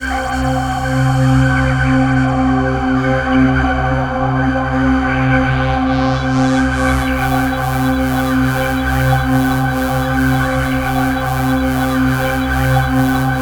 Index of /90_sSampleCDs/USB Soundscan vol.13 - Ethereal Atmosphere [AKAI] 1CD/Partition C/04-COBRA PAD